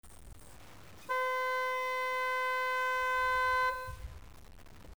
Klaxon voiture immobile -
Klaxon - Voiture immobile.mp3